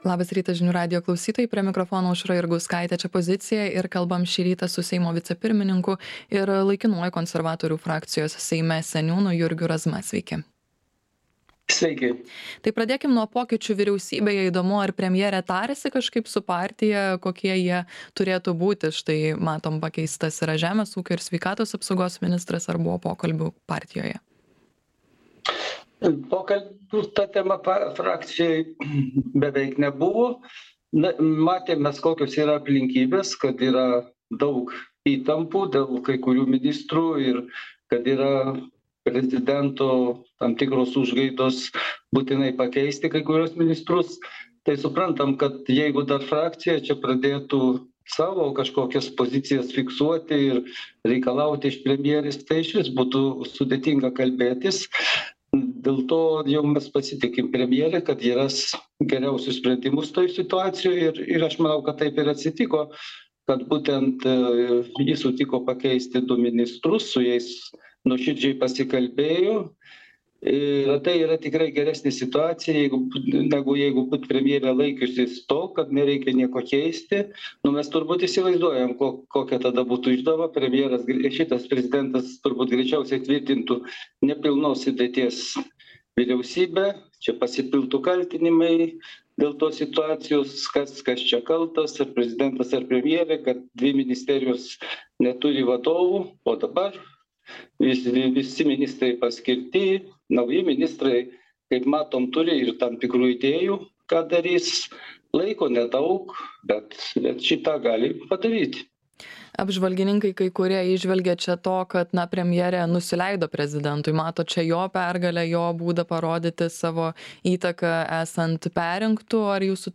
Laidoje dalyvauja Seimo vicepirmininkas, konservatorius Jurgis Razma.